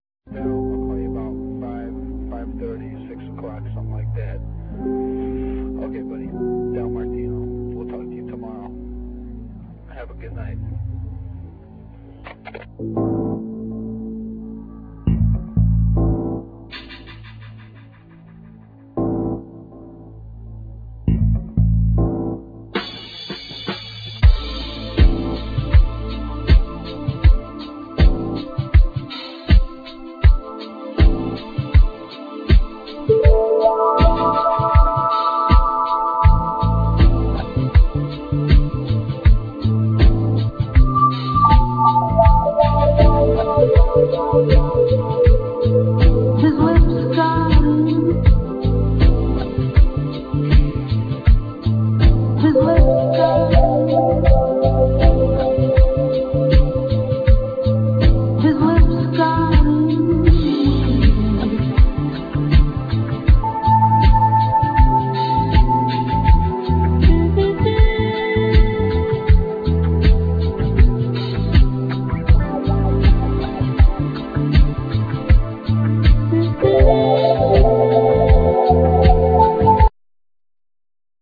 Trumpet,Flugelhorn
Bass,Keyboards,Guitar,Samples
Fender Rhodes
Drums
Vocals